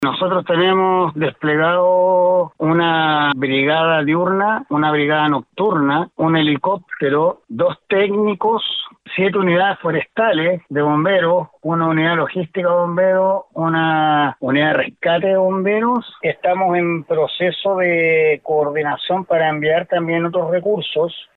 En conversación con el Director Regional de Senapred, Christian Cardemil, fue entregado un amplio balance de todos los recursos aéreos y terrestres ya enviados, tanto desde el mismo servicio, como de Bomberos y de la Corporación Nacional Forestal.